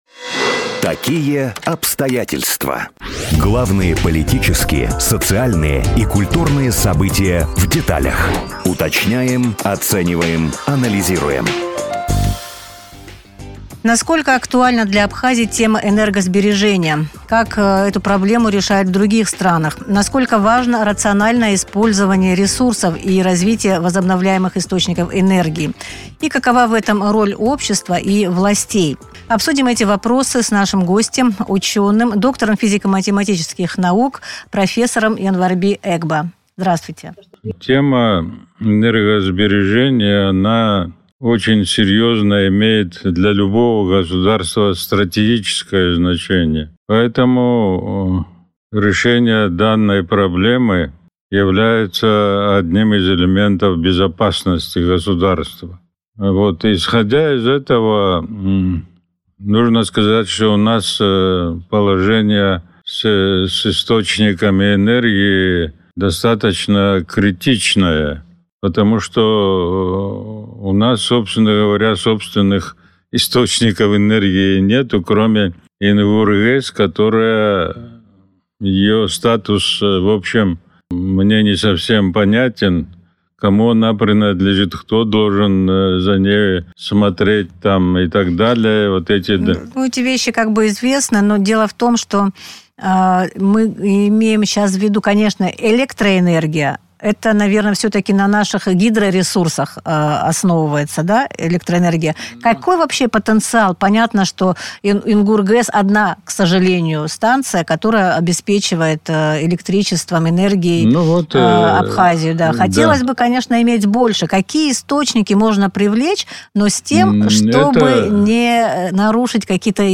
Об актуальности энергосбережения в Абхазии, об опыте других стран в эфире радио Sputnik рассказал доктор физико-математических наук, профессор